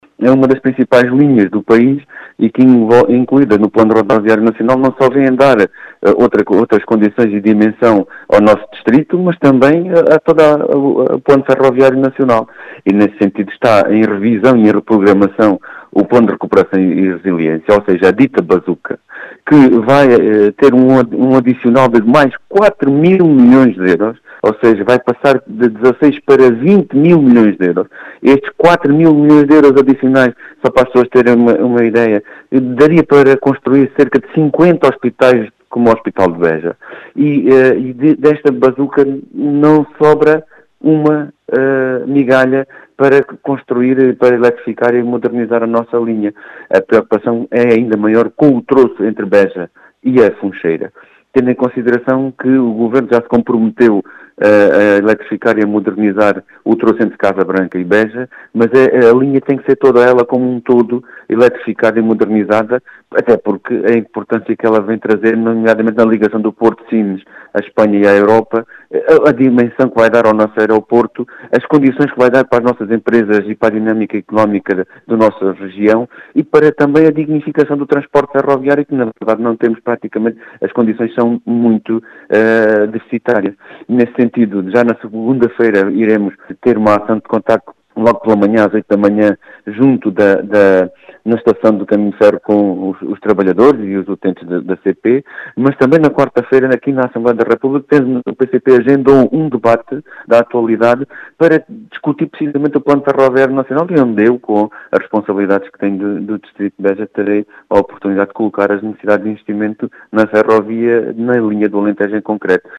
As explicações são do deputado do PCP, João Dias, que realça a importância deste troço ferroviário.